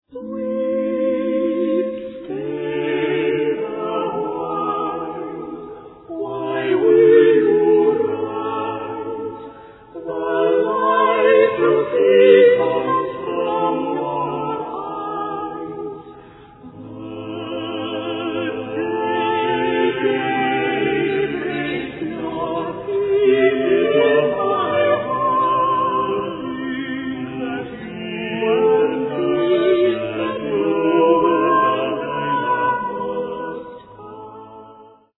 A stunning recording from England
for 4 voices & lute